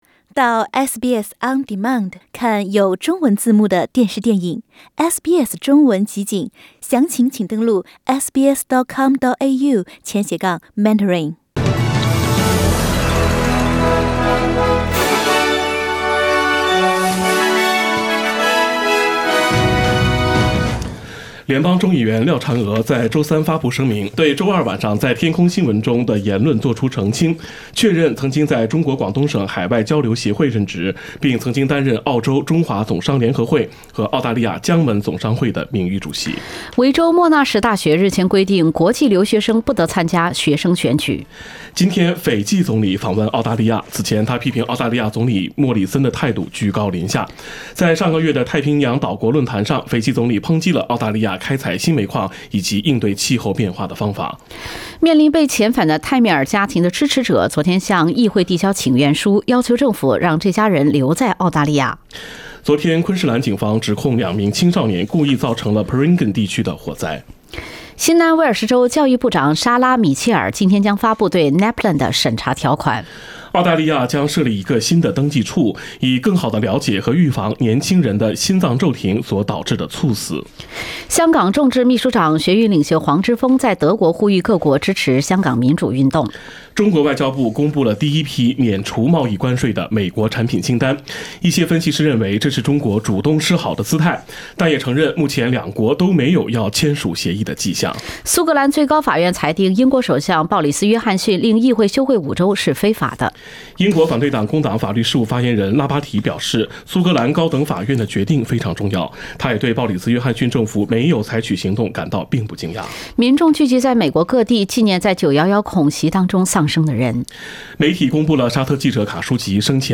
SBS早新闻 （9月12日）